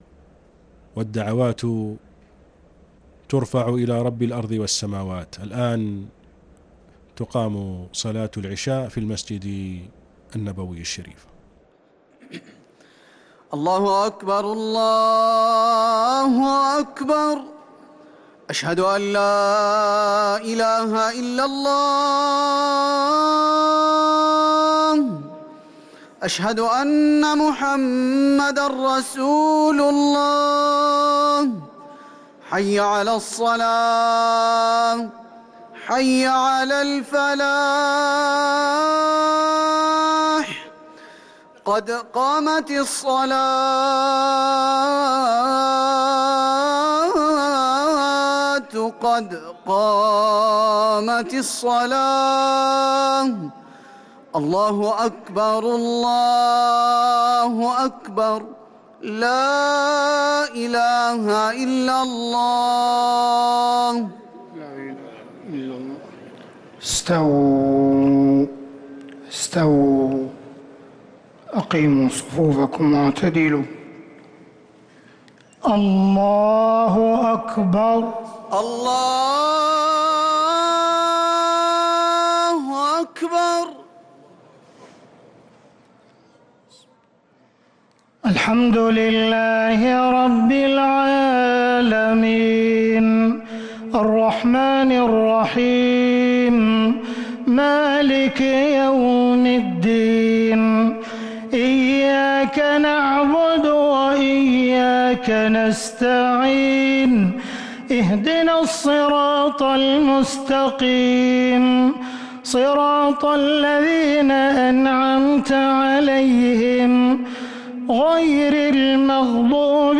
صلاة العشاء للقارئ عبدالرحمن السديس 8 رمضان 1444 هـ
تِلَاوَات الْحَرَمَيْن .